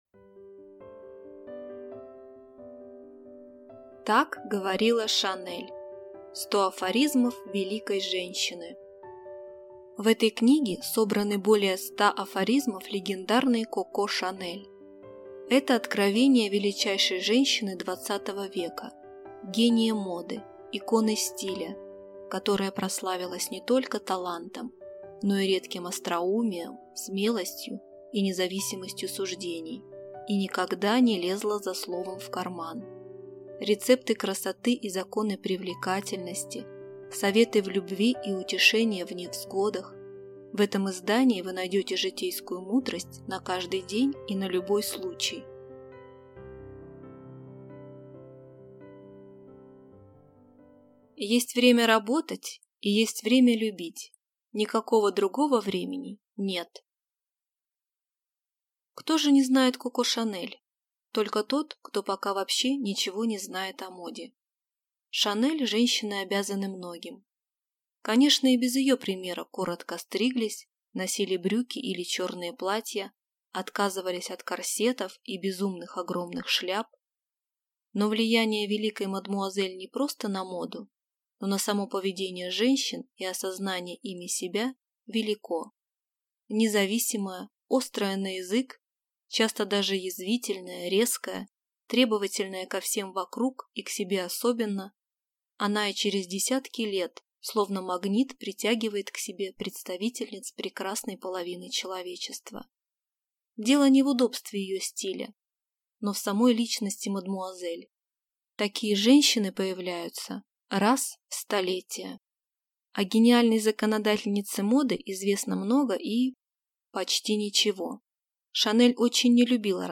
Аудиокнига Так говорила Шанель. 100 афоризмов великой женщины | Библиотека аудиокниг